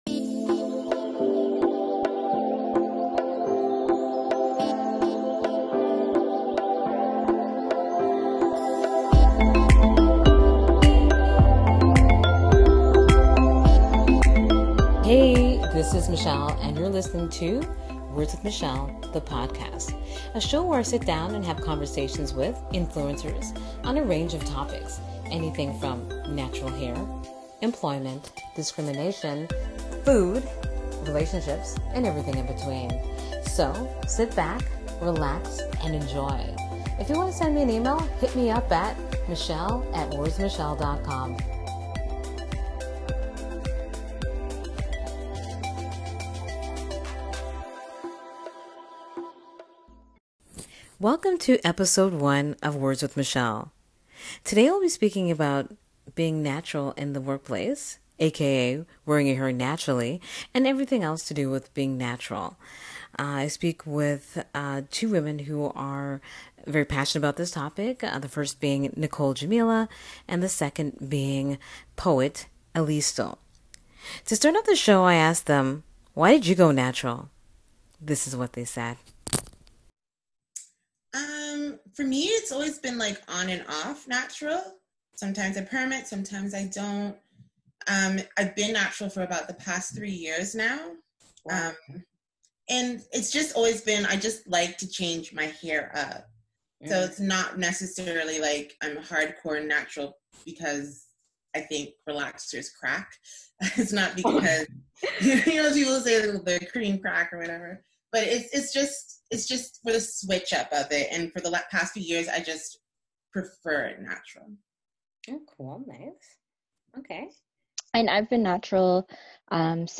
A discussion about wearing your hair naturally in the in the workplace.